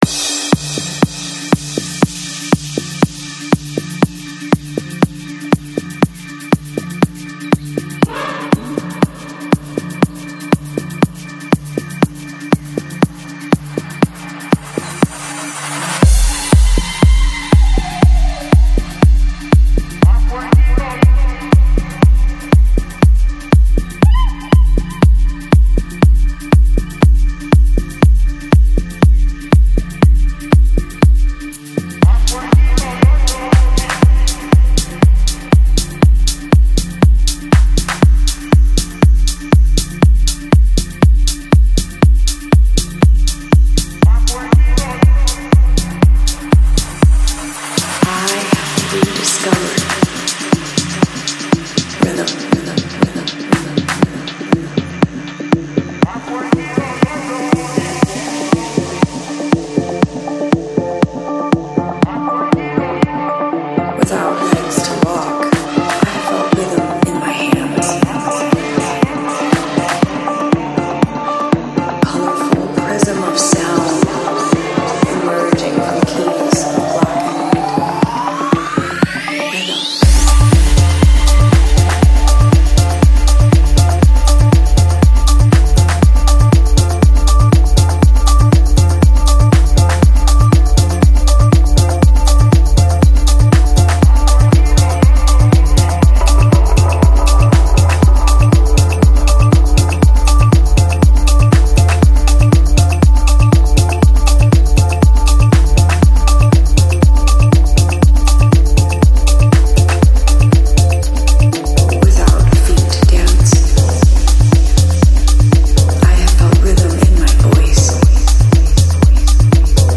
Жанр: Deep House, Tech House, Afro House